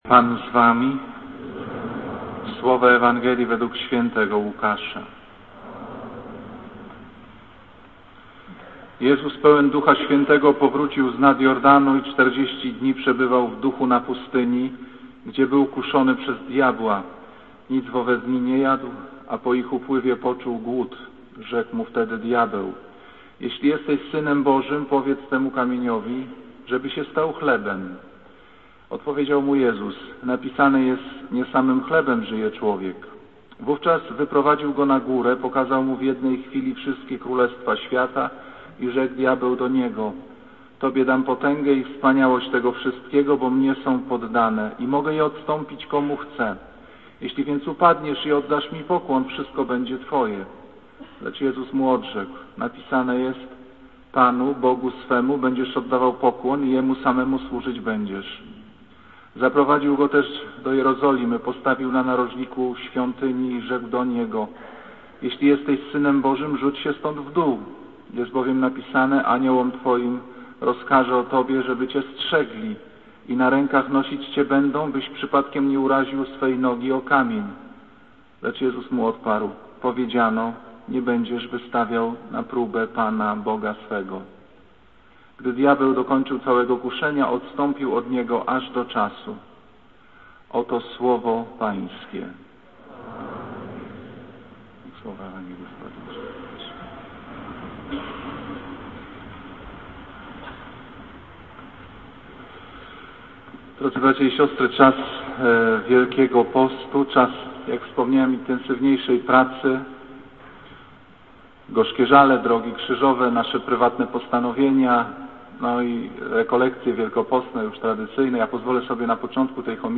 Kazanie z 28 października 2007r.